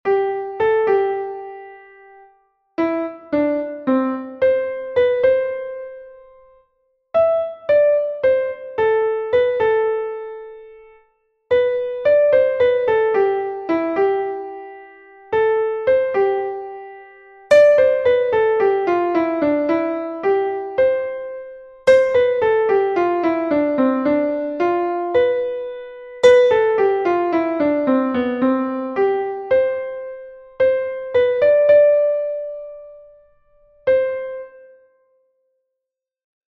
keeping the beat exercise 4